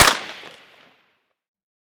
heav_crack_02.ogg